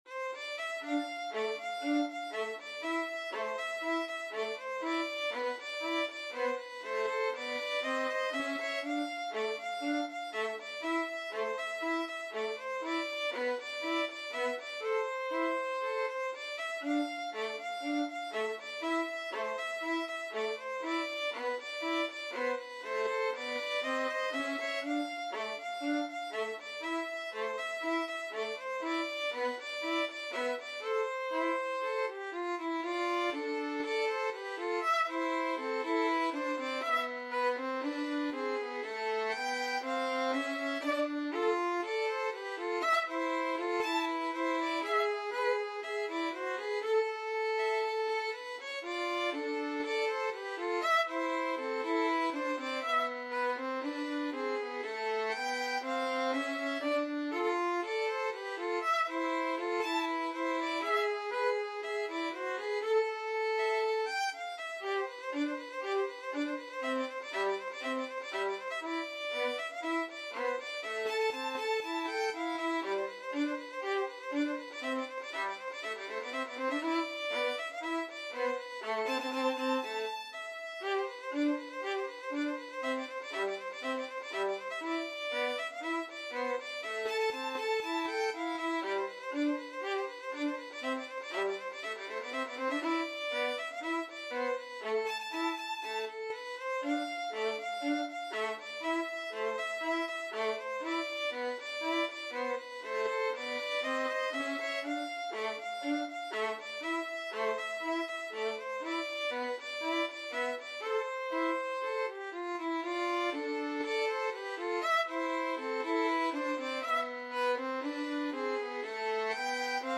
Free Sheet music for Violin Duet
Violin 1Violin 2
A minor (Sounding Pitch) (View more A minor Music for Violin Duet )
Fast Two in a Bar =c.120